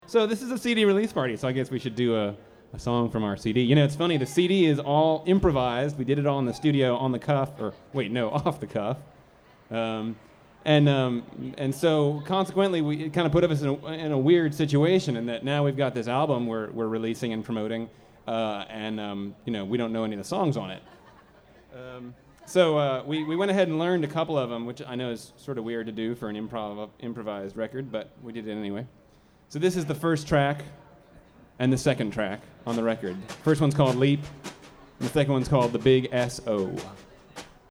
Last Concert Café, 10/23/04
Source 2: Nomad Jukebox III recording (w/ On-Stage Sony Stereo Mic) > WAV